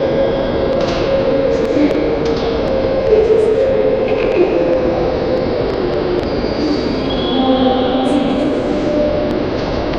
calm airport background noise loop
calm-airport-background-n-qirxfn7y.wav